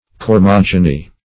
Search Result for " cormogeny" : The Collaborative International Dictionary of English v.0.48: Cormogeny \Cor*mog"e*ny\ (k[^o]r*m[o^]j"[-e]*n[y^]), n. [Gr. kormo`s trunk of a tree + root of gi`gnesqai to be born.]